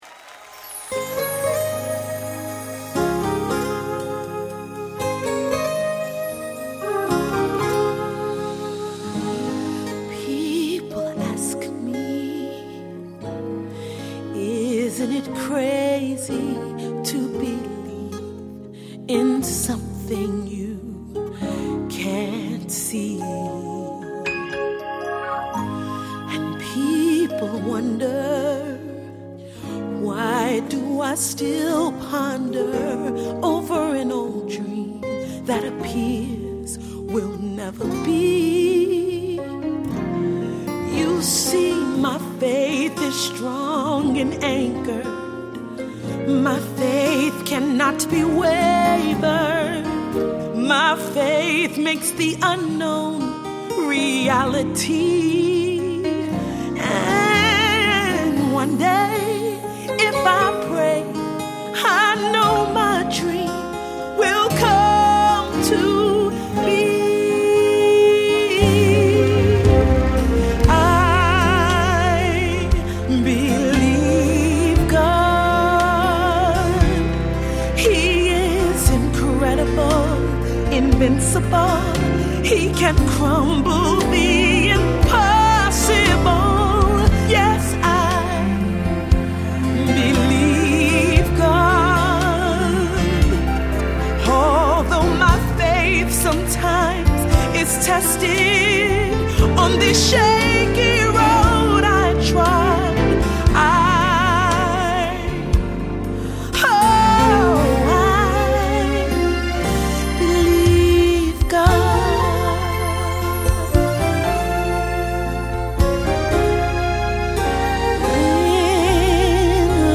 Orchestra/Leader/Choir